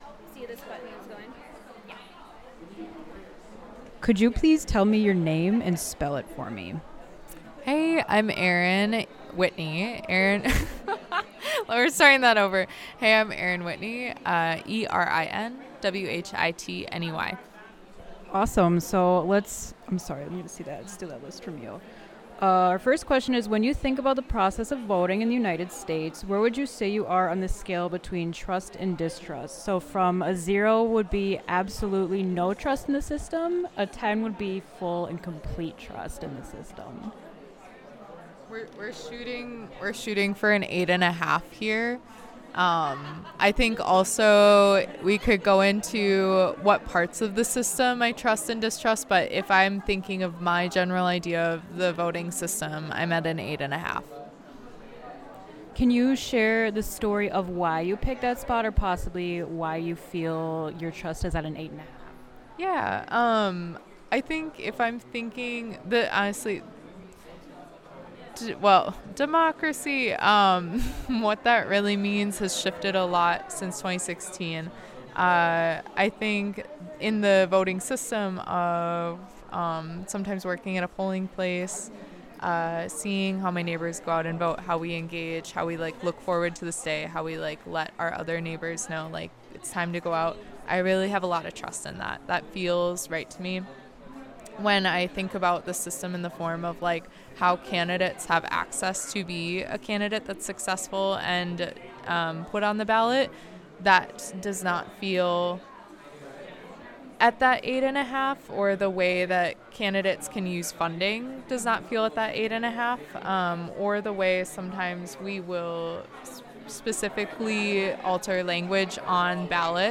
Interview
Location UEC Riverside